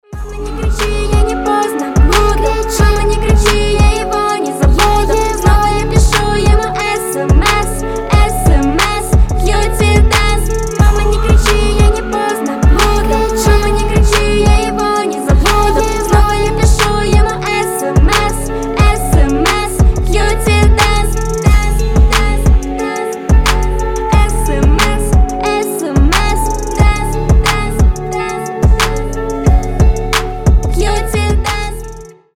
детский голос